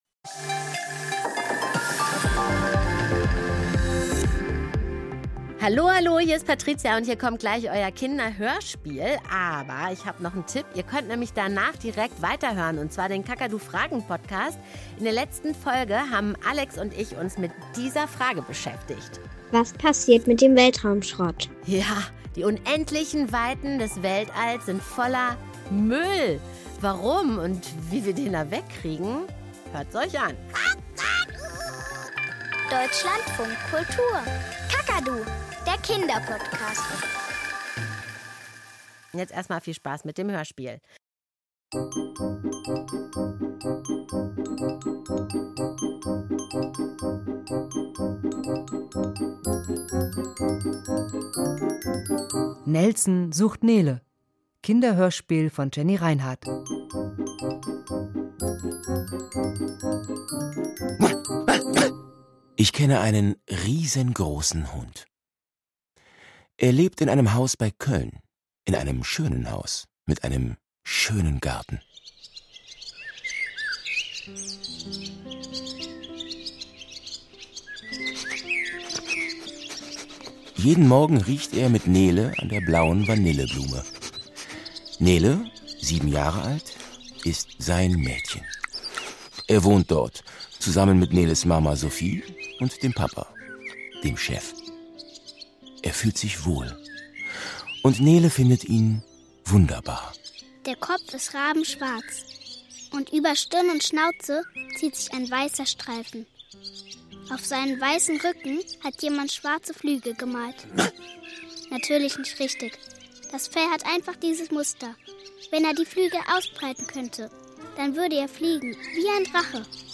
Kinderhörspiel - Nelson sucht Nele